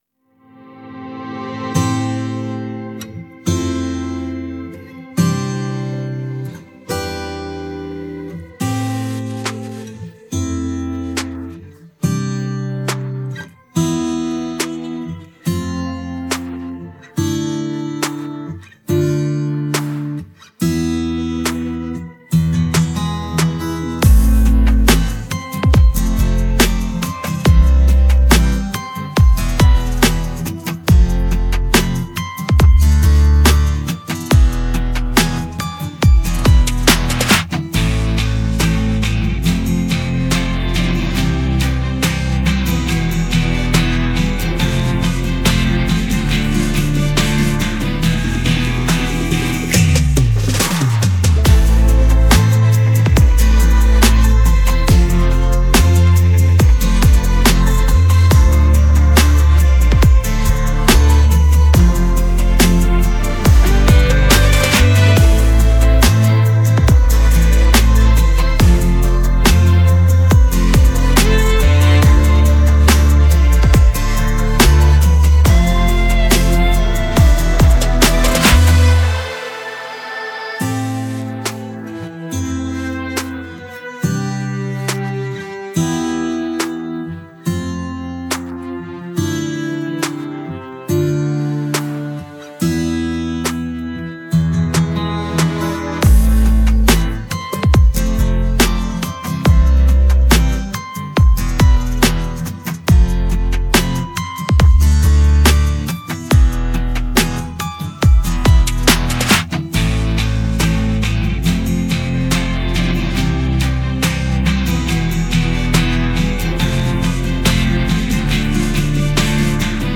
Детская песня
караоке